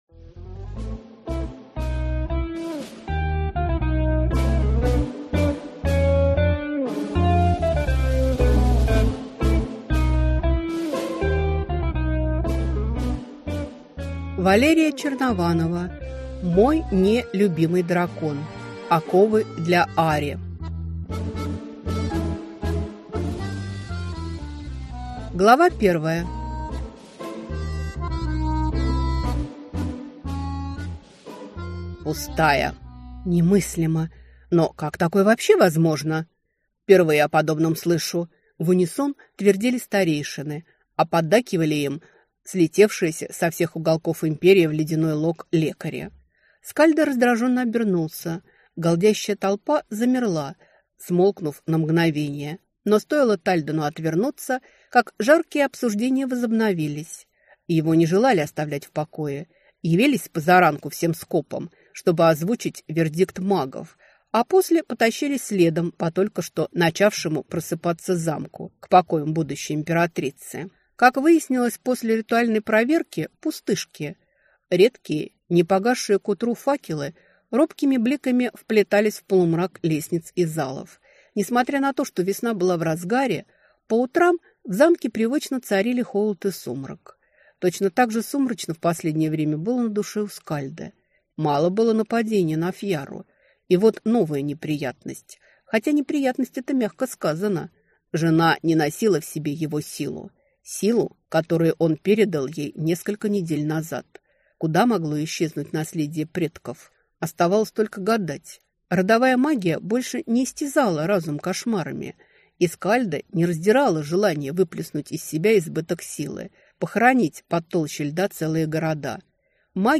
Аудиокнига Мой (не)любимый дракон. Оковы для ари | Библиотека аудиокниг